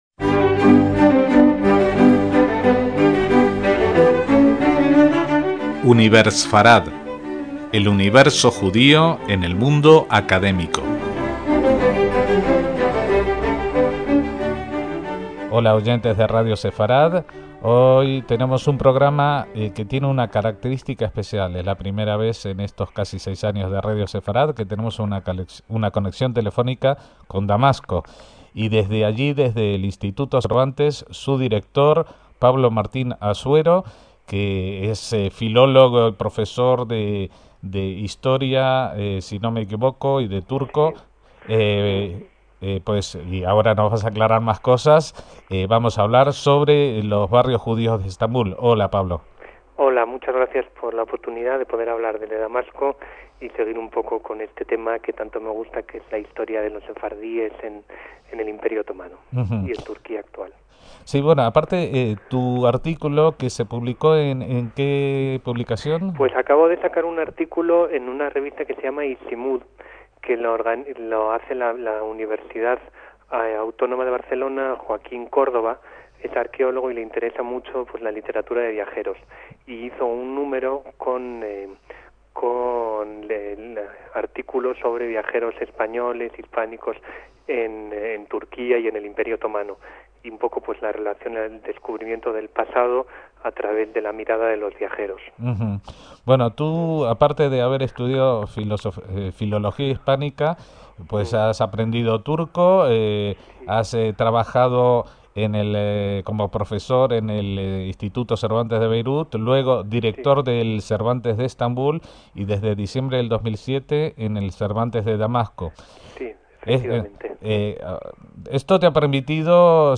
Hace 10 años tuvimos la oportunidad de entrevistar